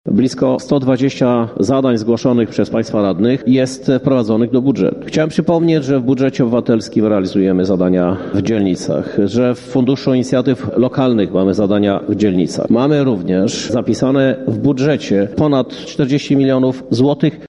Znaczna część działań w dzielnicach które mogą być realizowane znalazła się w budżecie – mówi prezydent miasta Krzysztof Żuk.